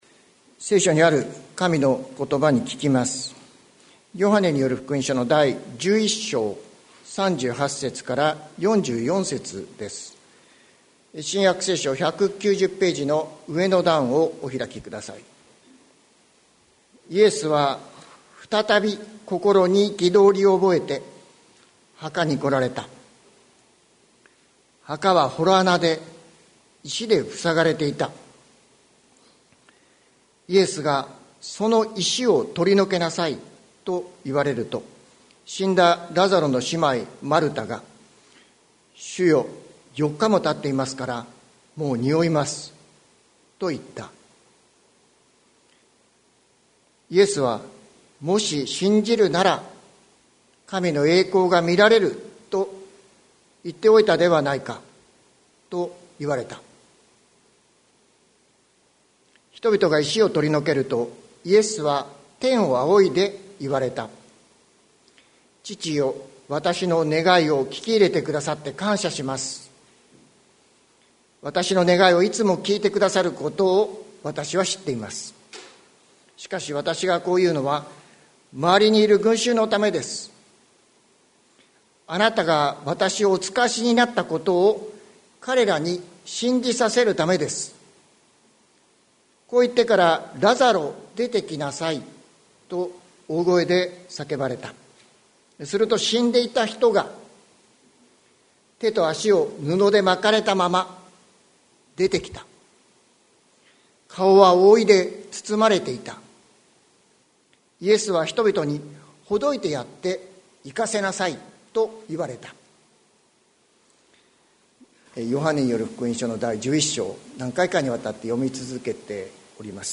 2023年02月05日朝の礼拝「いのちを呼び覚ます声」関キリスト教会
説教アーカイブ。